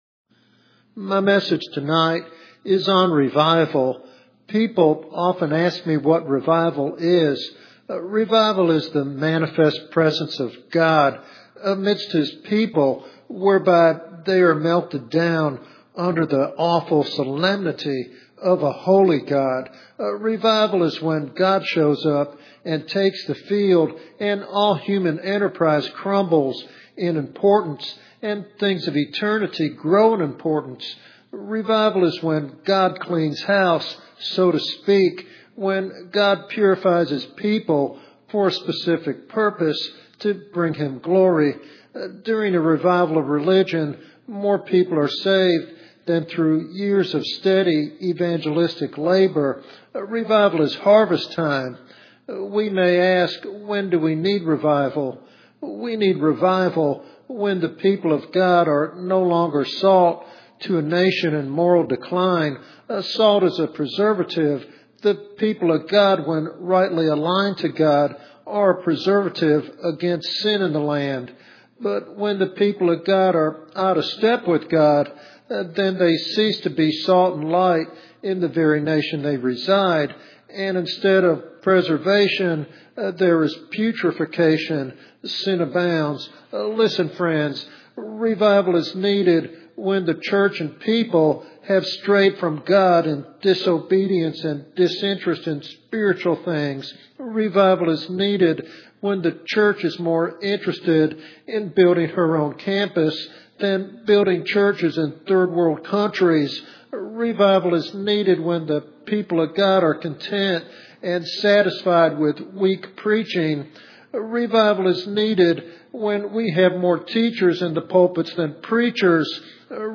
This teaching sermon provides both a theological framework and practical application for believers seeking revival in their lives and communities.